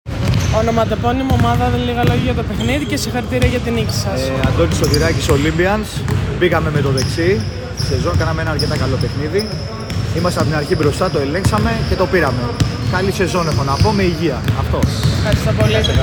GAMES INTERVIEWS: